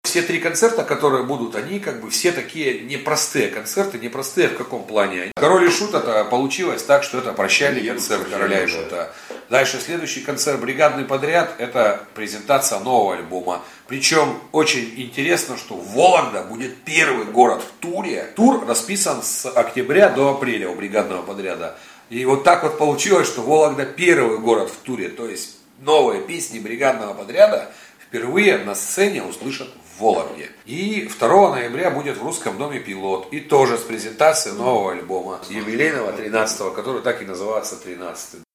эксклюзивном интервью